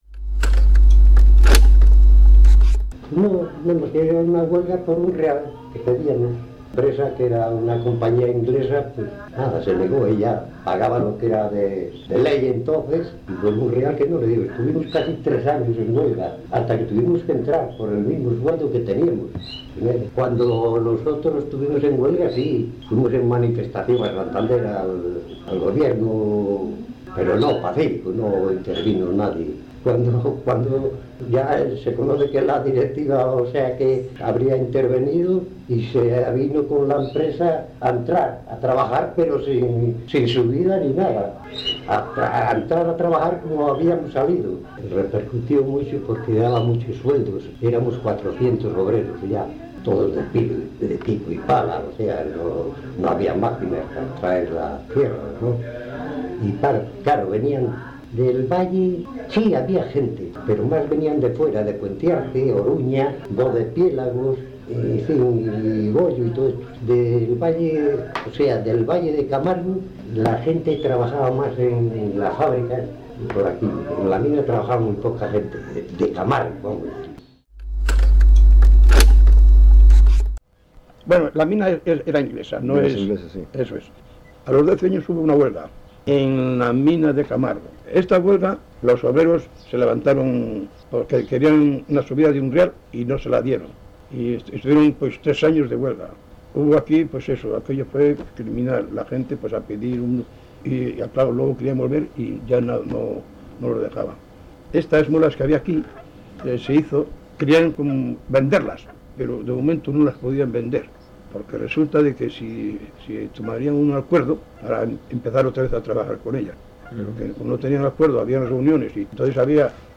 Historia oral del valle de Camargo